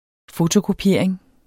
Udtale [ ˈfotokoˌpjeˀɐ̯eŋ ]